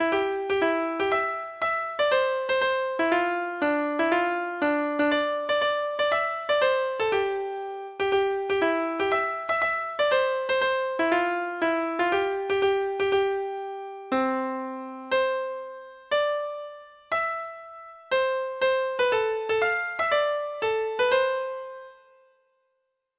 Traditional Trad. The Golden Vanity Treble Clef Instrument version
Traditional Music of unknown author.